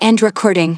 synthetic-wakewords
ovos-tts-plugin-deepponies_Naoto Shirogane_en.wav